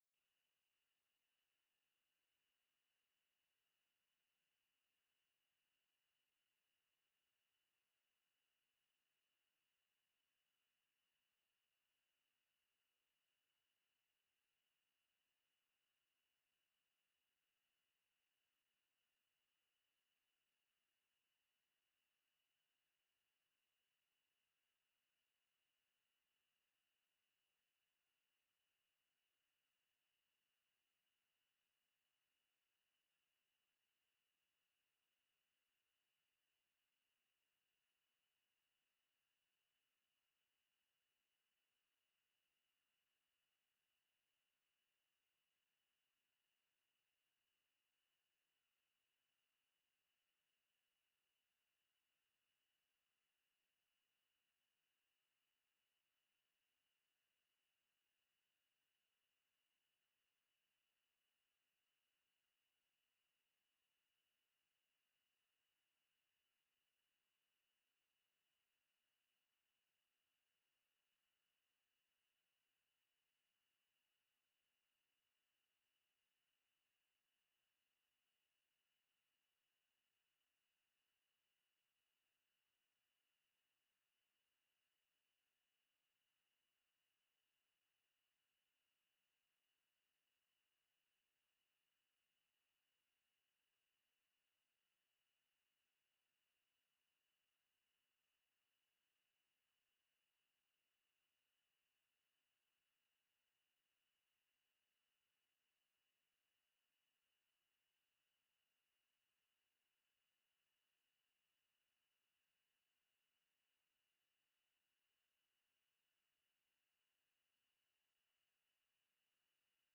30-minutes-of-silence.mp3